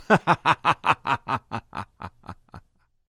Gendered social sounds
male_laugh2.ogg